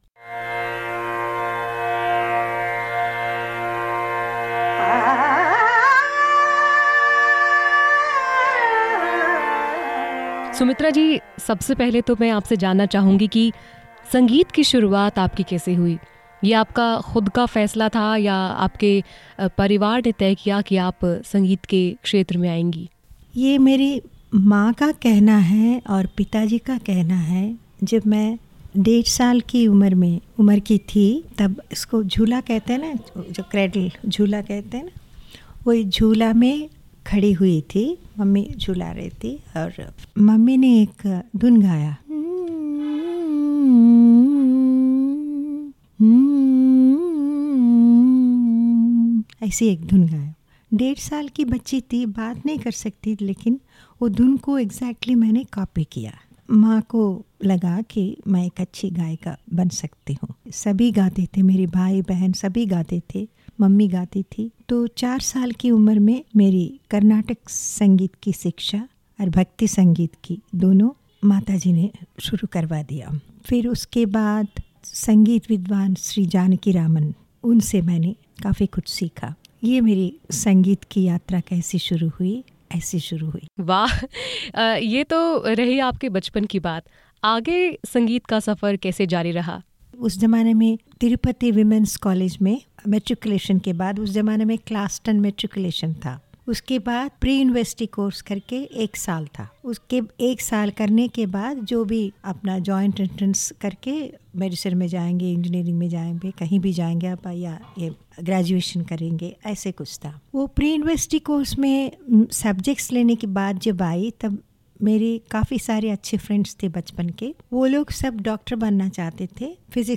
शास्त्रीय गायिका सुमित्रा गुहा
ख़ास बातचीत